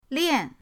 lian4.mp3